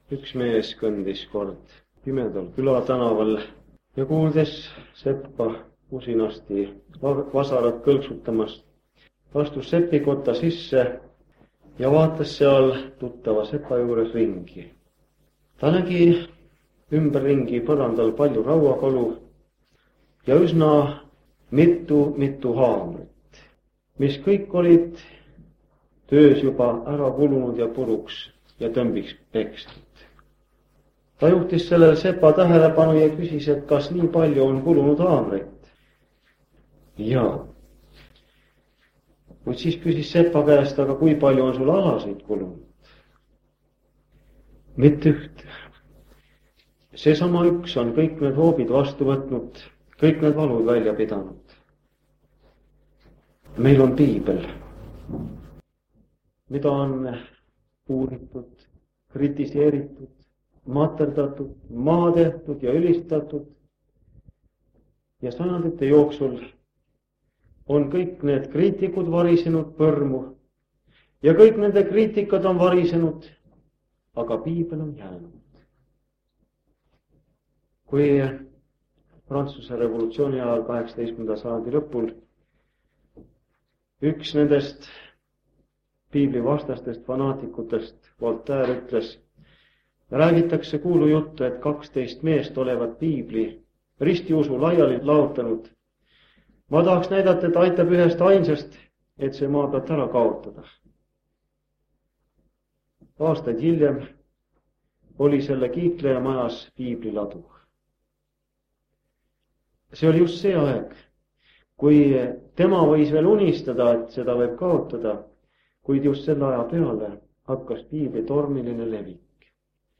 1983 aasta jutlus vanalt lintmaki lindlilt.
Jutlused